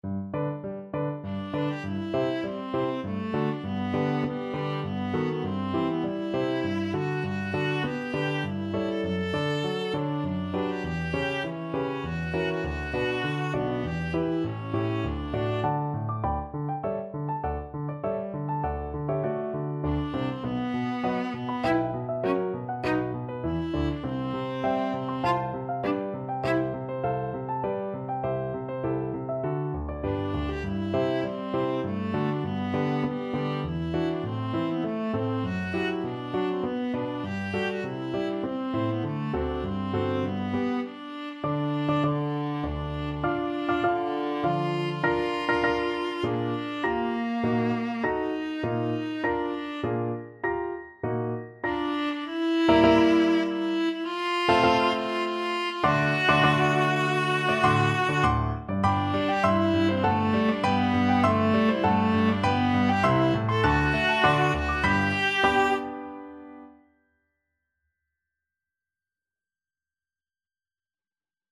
Classical Brahms, Johannes Kleine Hochzeits-Kantate, WoO 16 Viola version
Viola
3/4 (View more 3/4 Music)
G major (Sounding Pitch) (View more G major Music for Viola )
~ = 100 Tempo di Menuetto
kleine-hochzeits-kantate-woo-16_VLA.mp3